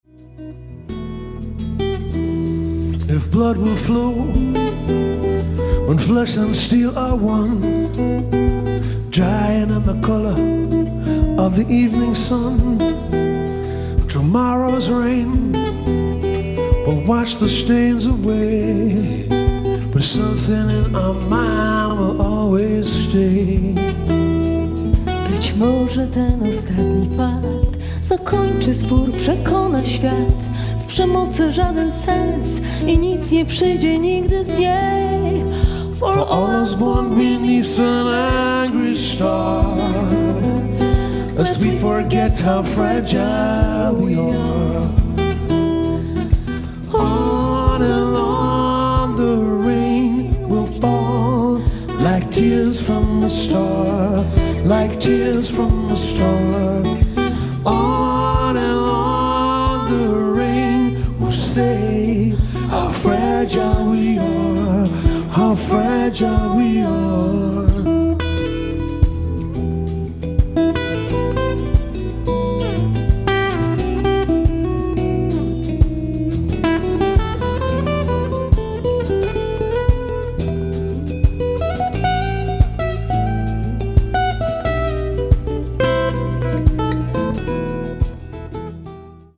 ライブ・アット・トルン、ポーランド 12/08/2016